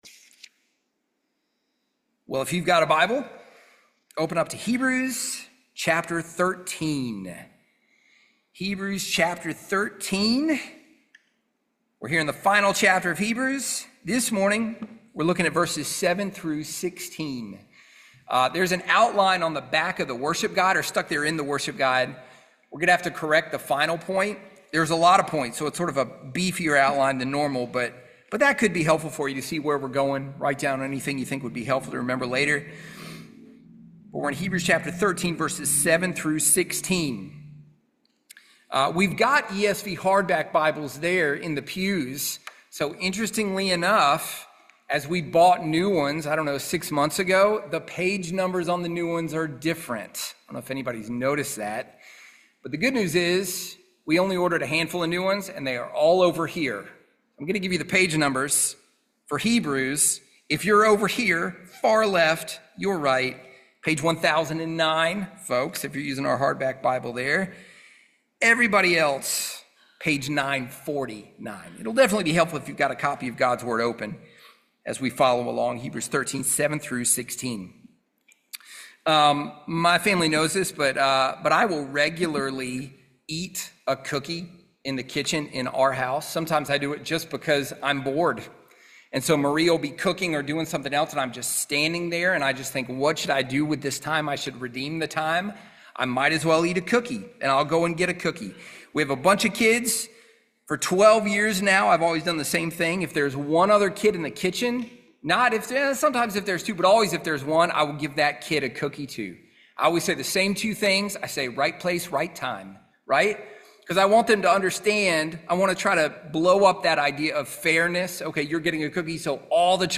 Expositorypreaching